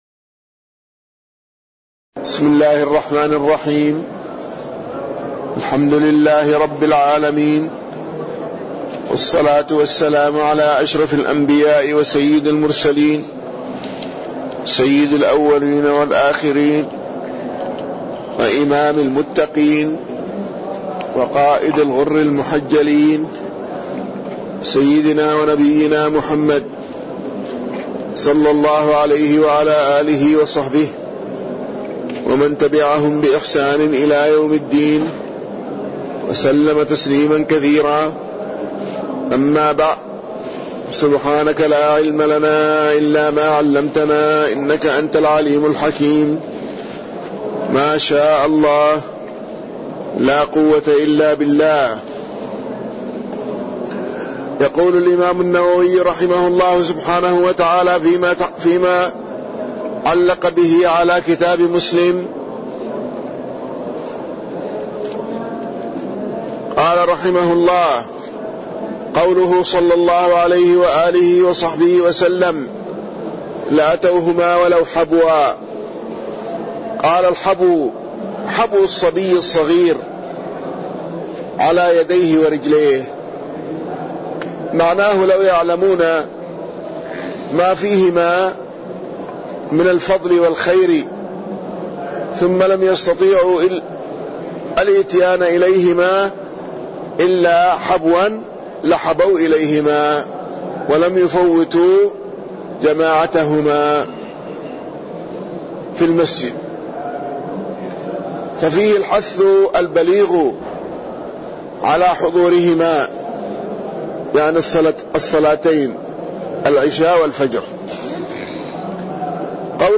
تاريخ النشر ٧ ربيع الأول ١٤٣٠ هـ المكان: المسجد النبوي الشيخ